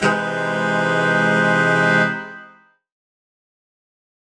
UC_HornSwellAlt_Cmin6maj7.wav